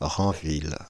Ranville (French pronunciation: [ʁɑ̃vil]
Fr-Ranville.ogg.mp3